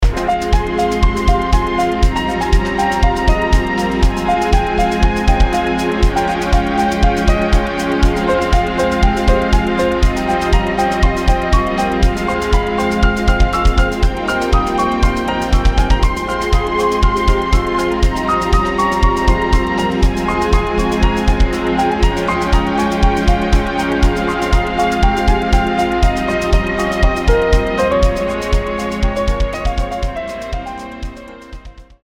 Streicher im Hintergrund mit verschiedener Dynamik gemischt
Ein kleiner Versuch mit Automationen kleine crescendi, decrescendi und leichte Stufendynamik zu mischen um von gleichmäßigen Streicherflächen wegzukommen.